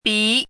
chinese-voice - 汉字语音库
bi2.mp3